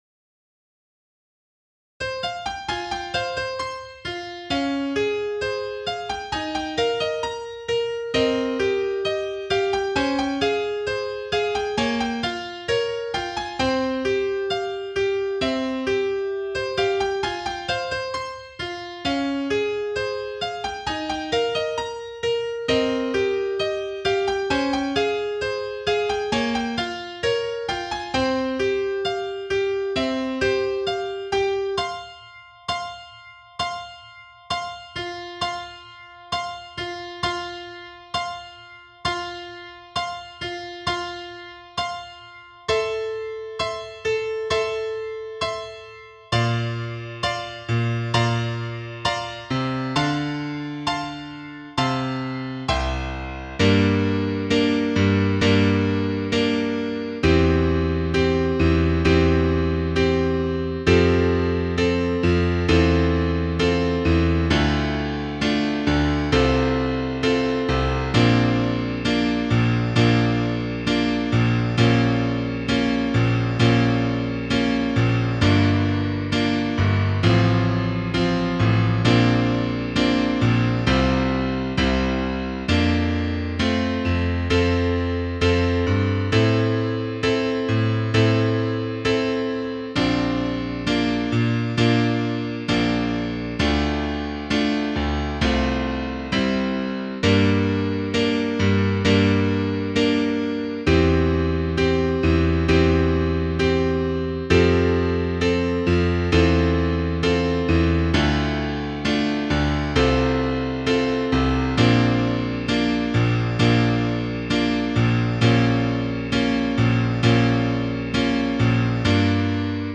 Piano
9_Suscepit_Israel_Piano.mp3